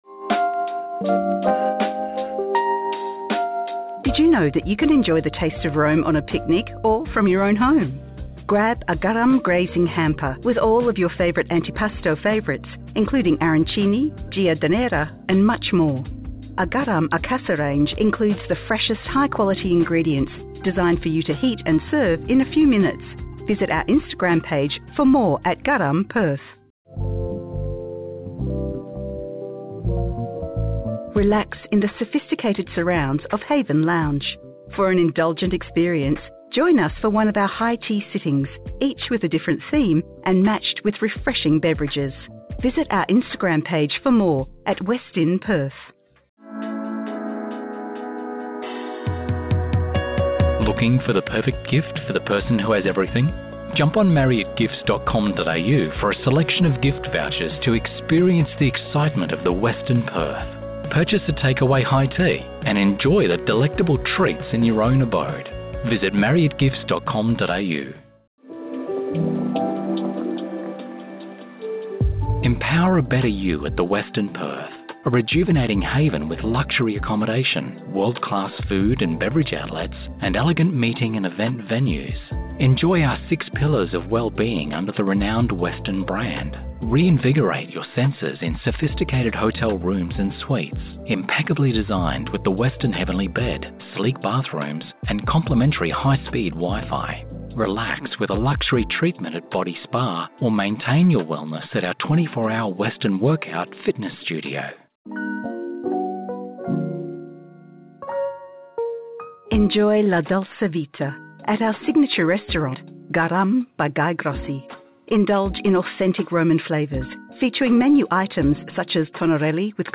A better customer experience – On Hold